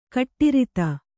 ♪ kaṭṭirita